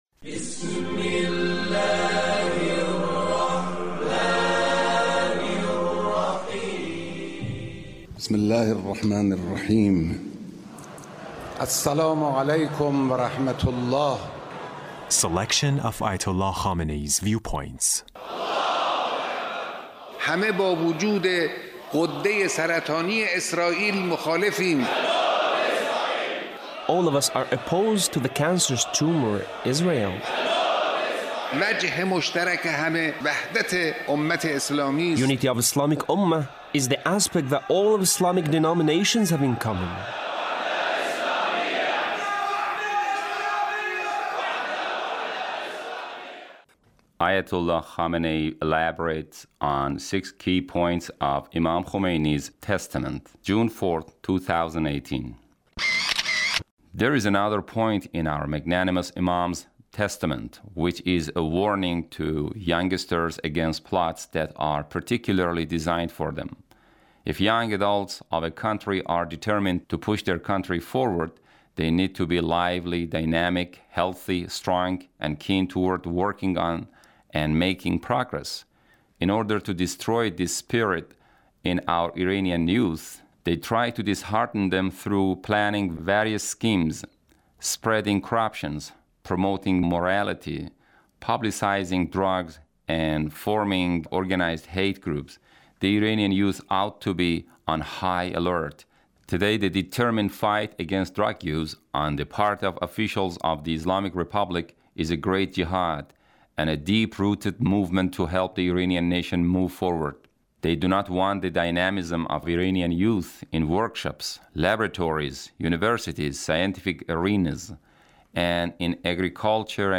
Leader's Speech about Imam Khomeini